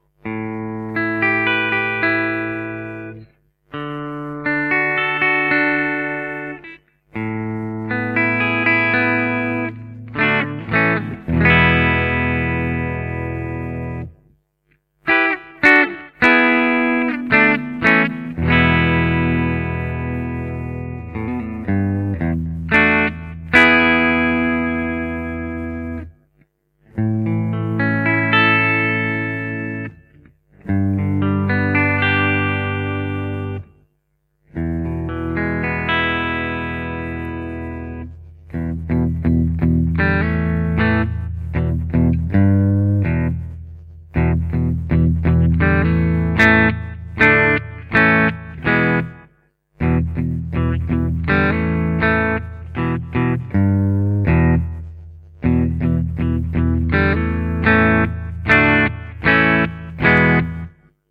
A vintage alnico 2 humbucker with unbalanced coils.
Still a great blues pickup but the extra mids and slightly edgy feel make classic rock the prime target for this pickup.
Soft magnet with moderate winding and unbalanced coils.
Bridge Full      Bridge Tapped        Neck Full      Neck Tapped